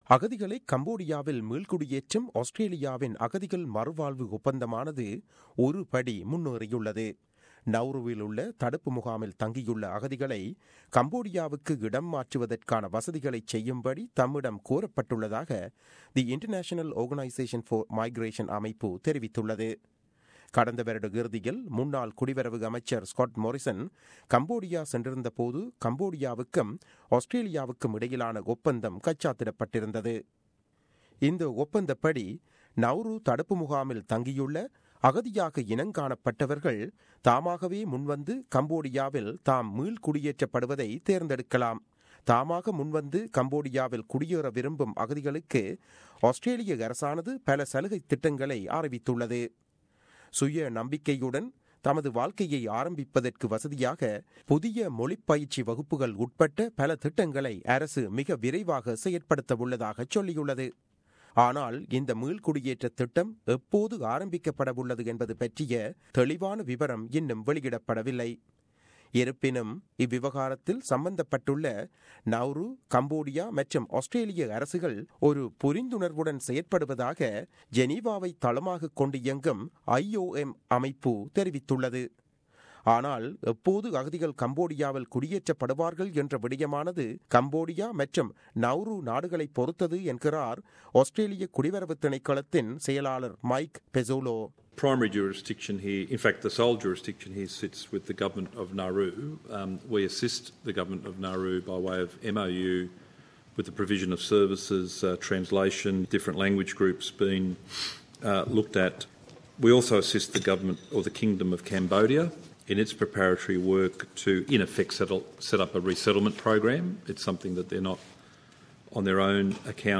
செய்தி விவரணம்